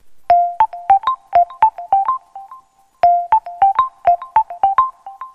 Стандартный рингтон